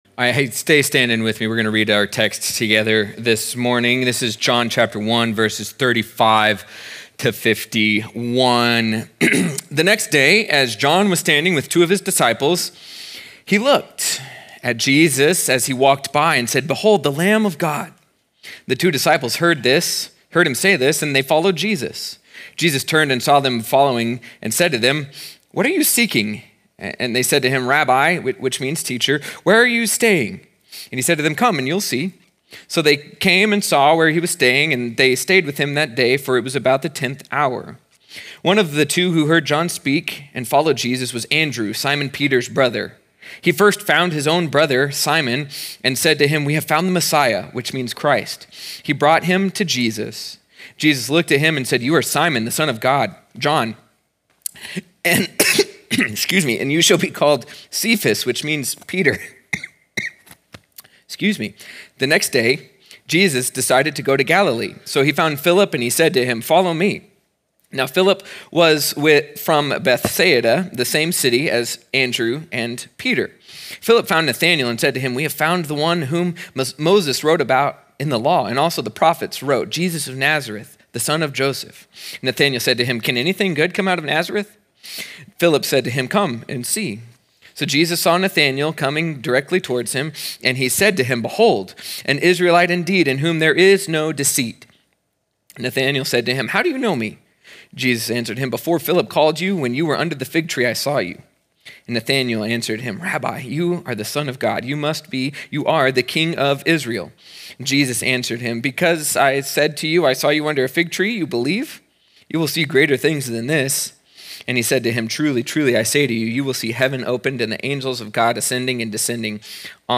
sermon audio 0201.mp3